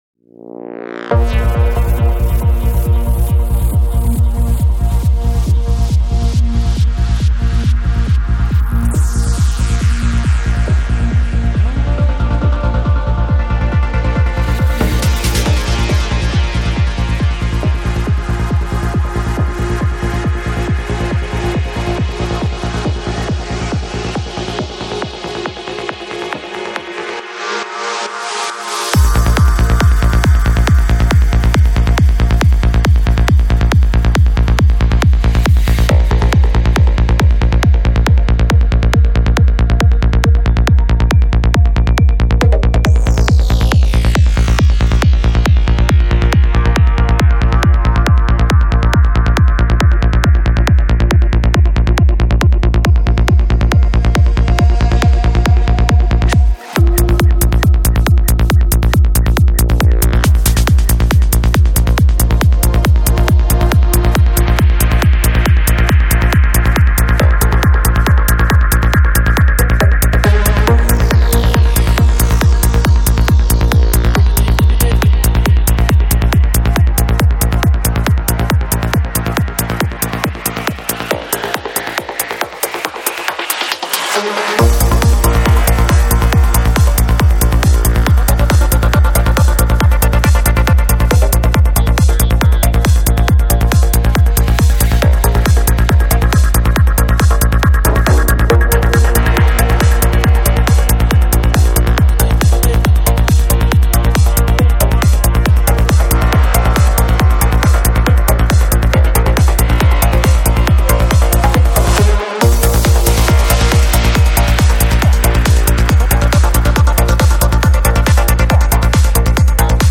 Жанр: Psychedelic
Psy-Trance Скачать 6.28 Мб 0 0 0